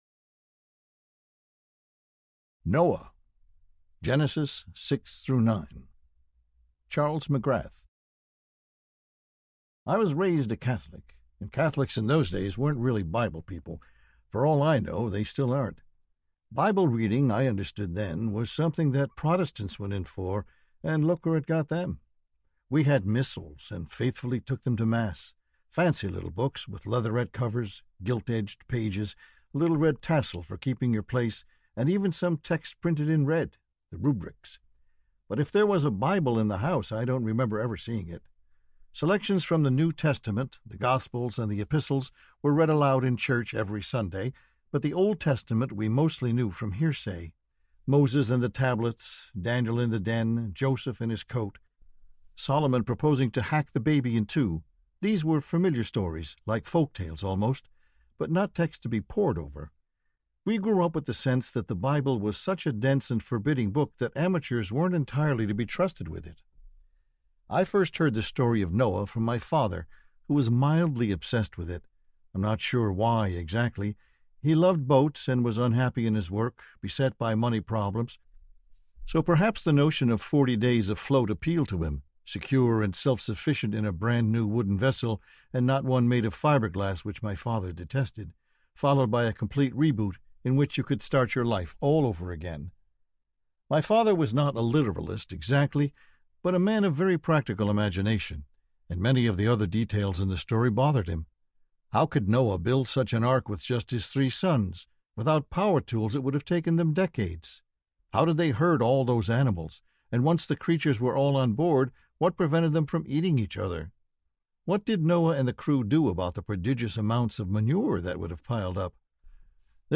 The Good Book Audiobook
9.0 Hrs. – Unabridged